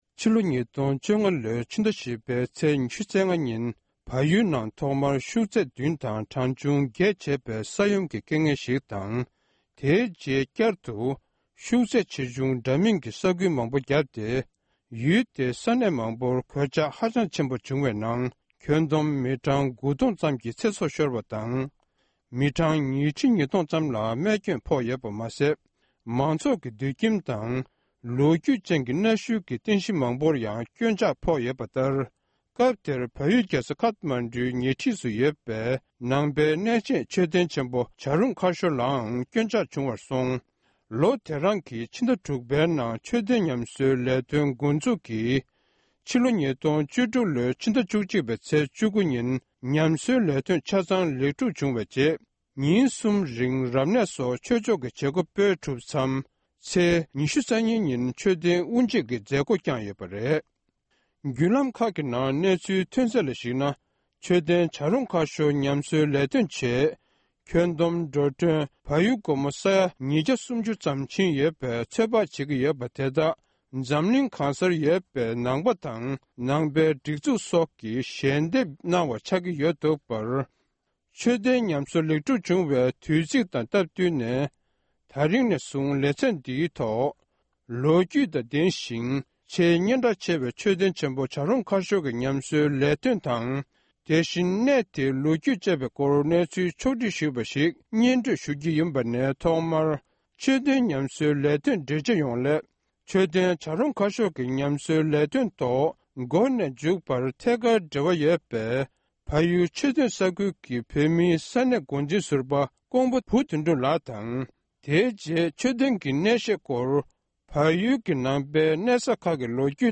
མཆོད་རྟེན་བྱ་རུང་ཁ་ཤོར་གྱི་ཉམས་གསོའི་ལས་དོན། ལེ་ཚན་དང་པོ། སྒྲ་ལྡན་གསར་འགྱུར།